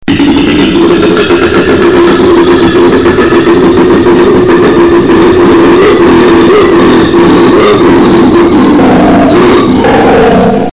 socoala.mp3